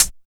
18 HAT    -L.wav